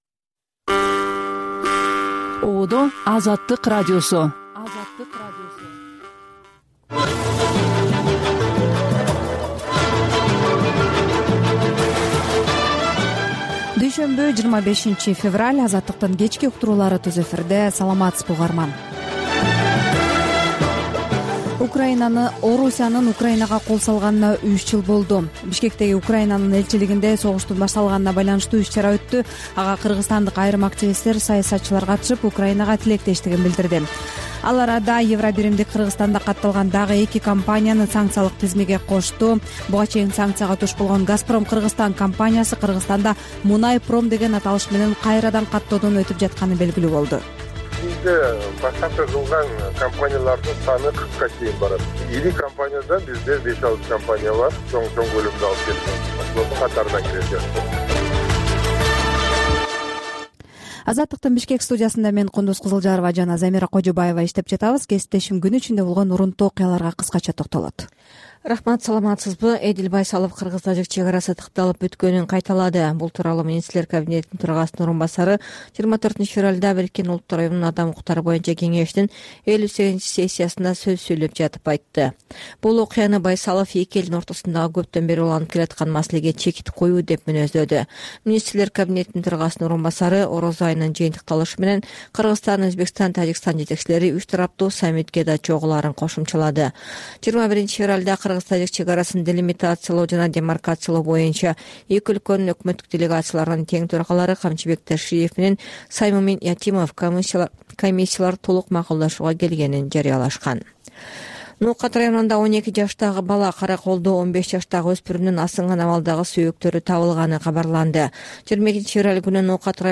Кечки радио эфир | 24.02.2025 | Кыргызстан Газадан палестиндерди күчтөп чыгаруу сунушуна каршы